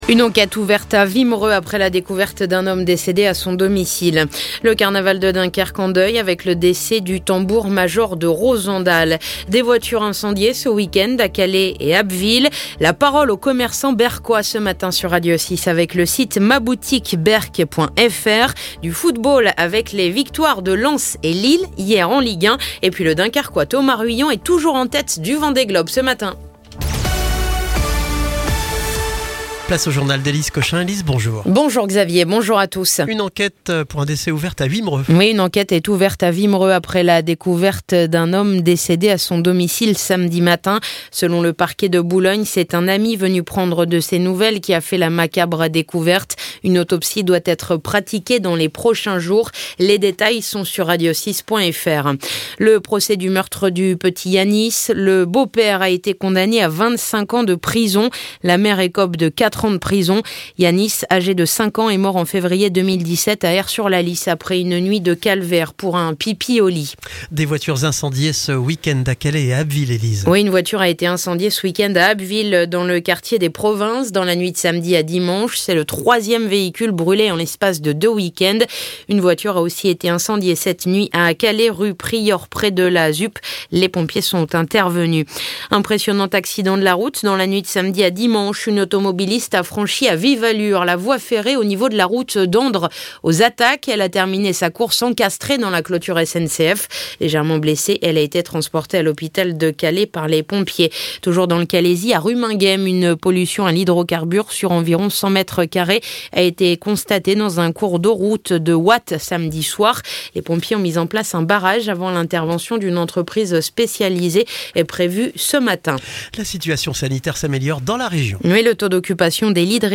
Le journal du lundi 23 novembre